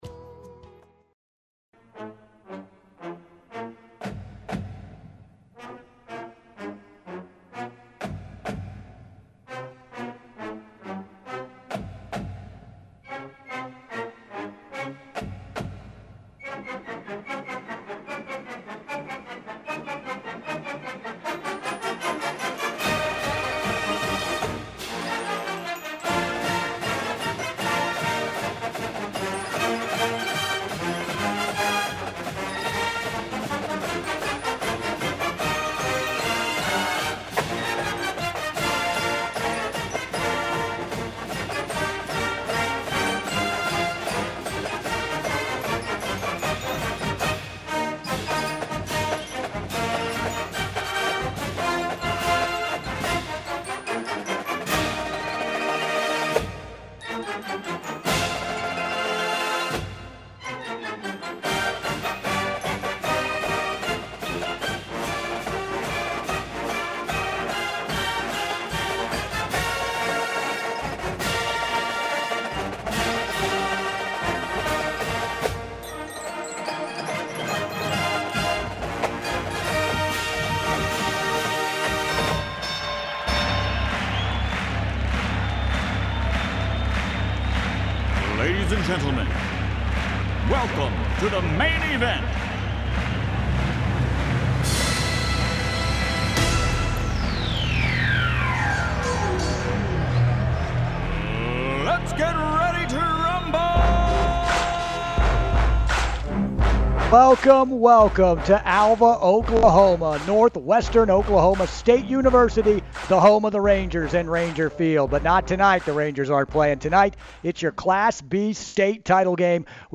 Our Road to the top coverage Concludes in Class B with the State Title Game from Northwestern Oklahoma State University.